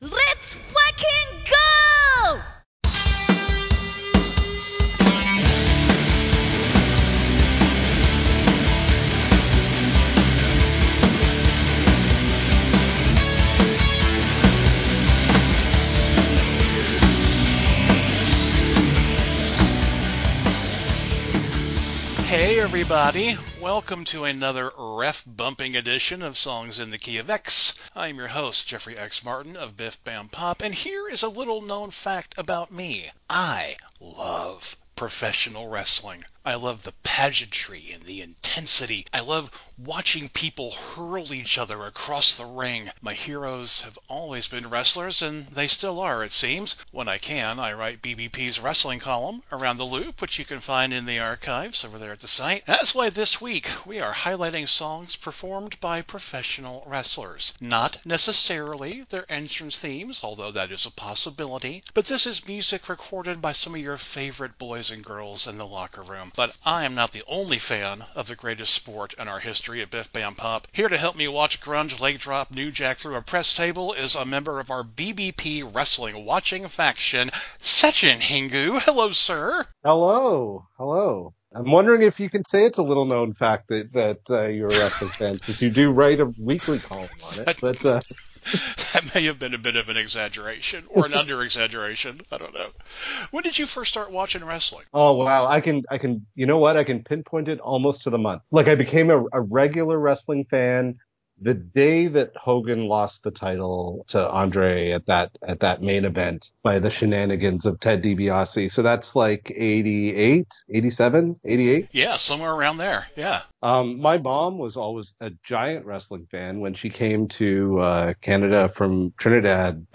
There might be a couple of bad words in here.